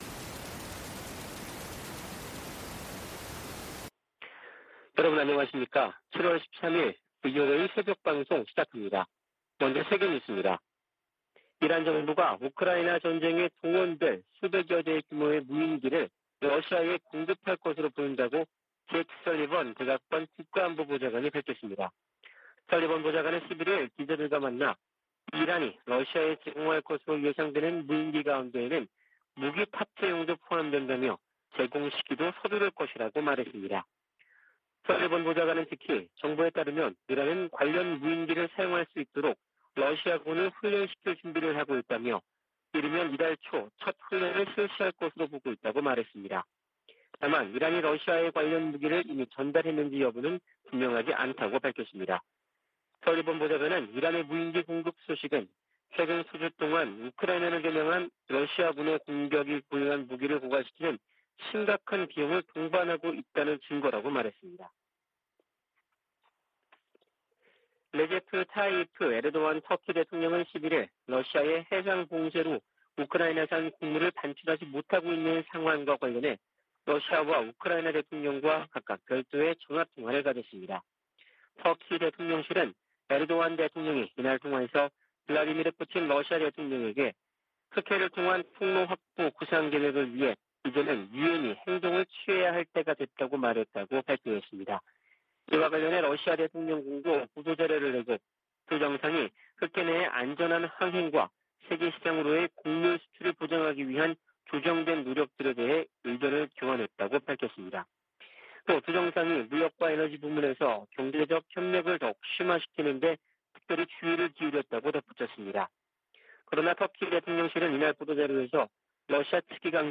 VOA 한국어 '출발 뉴스 쇼', 2022년 7월 13일 방송입니다. 올 후반기 미-한 연합지휘소훈련이 다음달 22일부터 9월1일까지 실시될 전망입니다. 북한 군이 4주 만에 방사포 발사를 재개한 것은 한국을 실제로 타격하겠다는 의지와 능력을 과시한 것이라고 전문가들은 진단했습니다. 마크 에스퍼 전 미 국방장관은 주한미군에 5세대 F-35 스텔스기를 배치해야 한다고 주장했습니다.